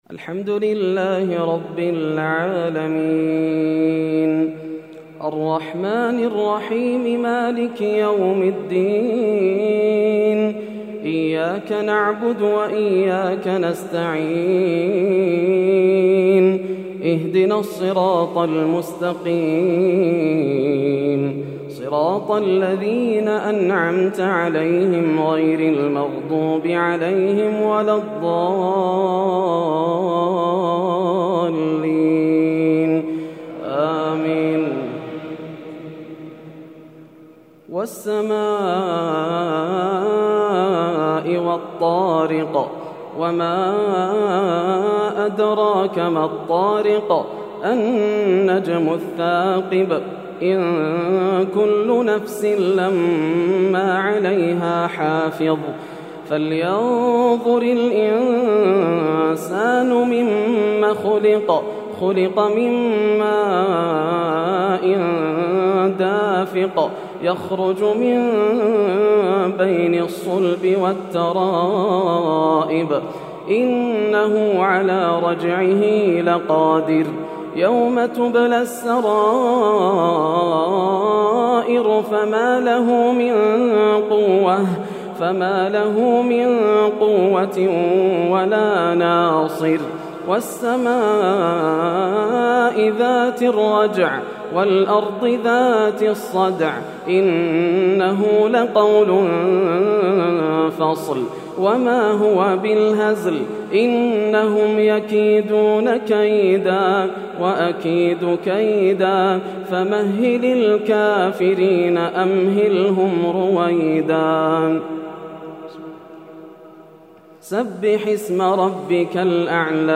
ليلة 29 رمضان 1431هـ من سورة الطارق حتى سورة الناس > الليالي الكاملة > رمضان 1431هـ > التراويح - تلاوات ياسر الدوسري